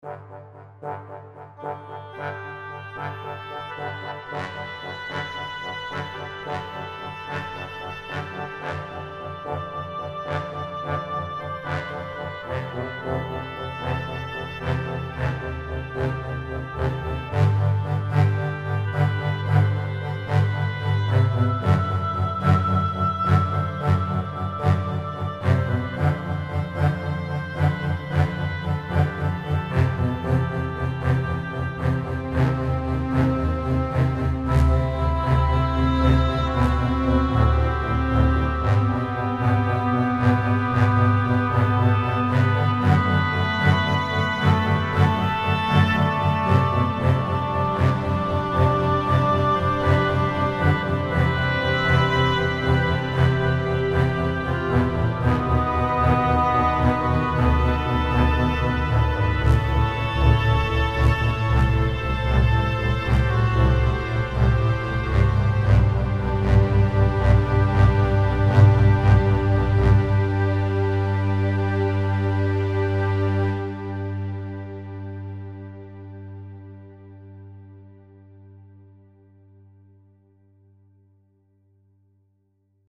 классическая интерлюдия выдержанная в академическом стиле: струнная и духовая секции. музыка для кино.